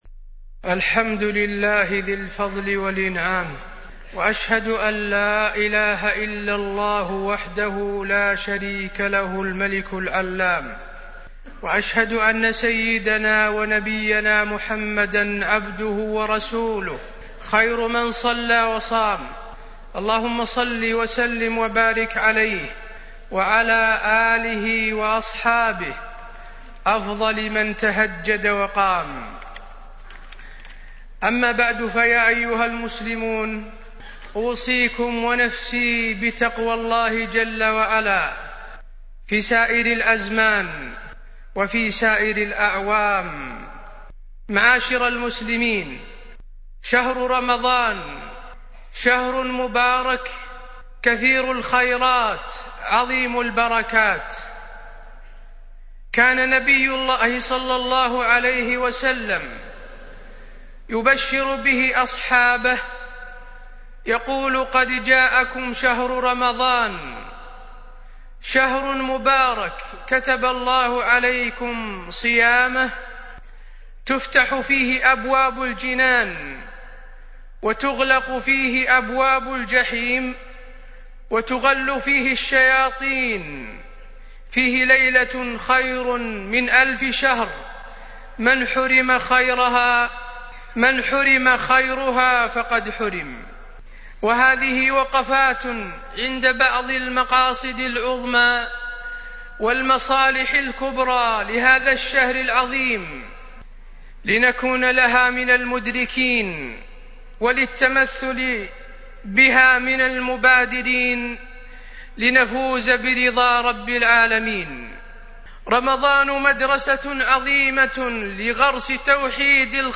تاريخ النشر ٦ رمضان ١٤٢٧ هـ المكان: المسجد النبوي الشيخ: فضيلة الشيخ د. حسين بن عبدالعزيز آل الشيخ فضيلة الشيخ د. حسين بن عبدالعزيز آل الشيخ فضل الصيام